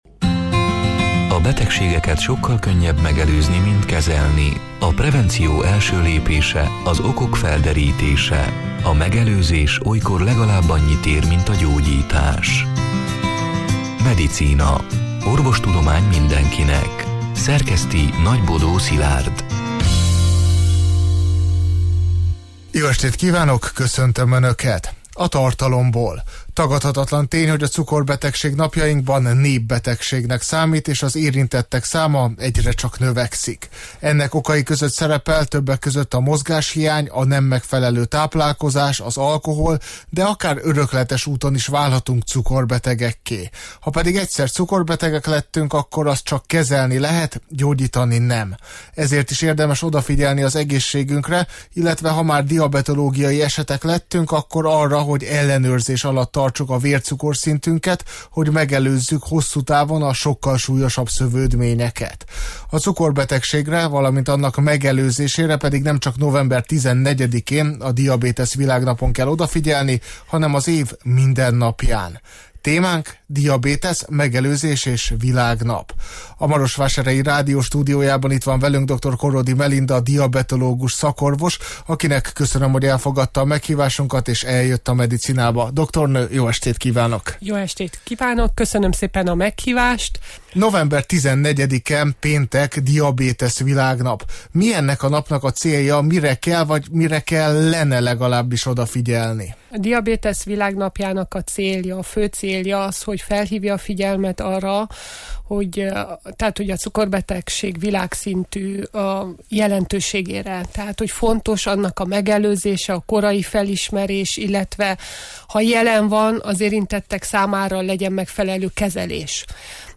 A Marosvásárhelyi Rádió Medicina (elhnagzott: 2025. november 12-én, szerdán este nyolc órától) c. műsorának hanganyaga: